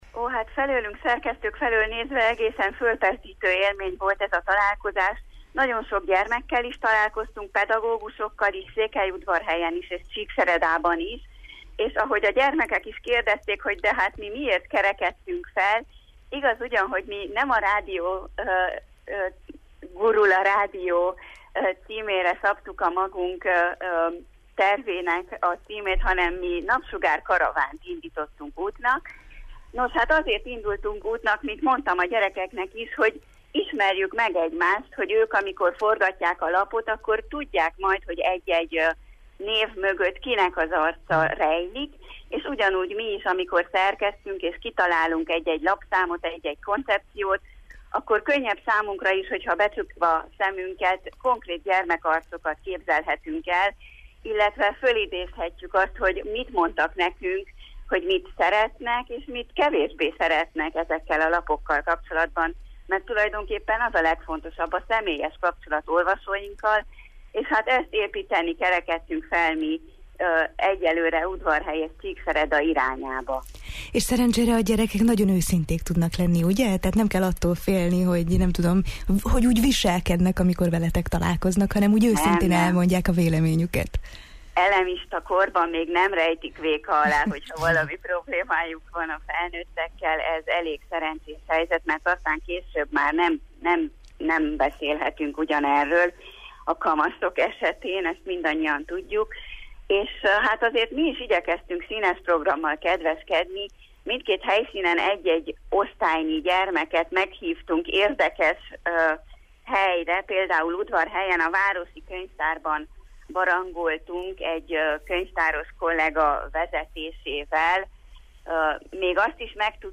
vidám beszámolóval jelentkezett a Jó reggelt, Erdély!-ben.